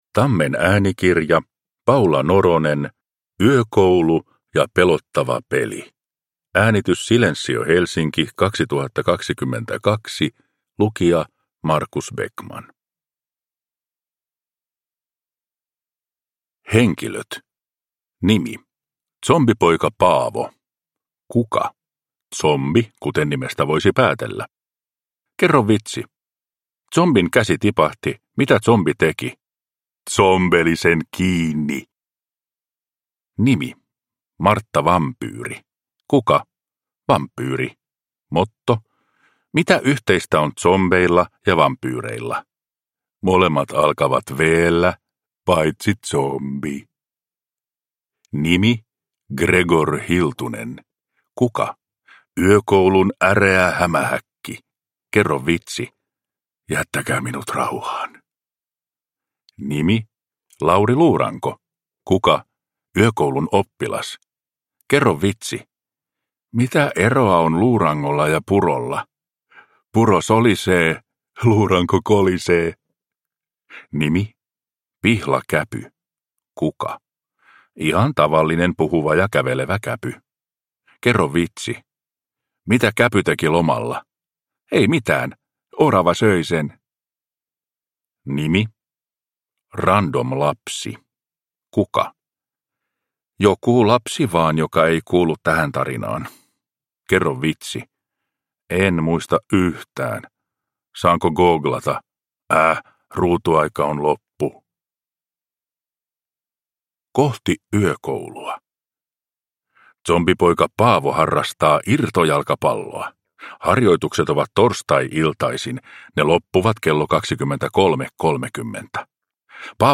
Yökoulu ja pelottava peli – Ljudbok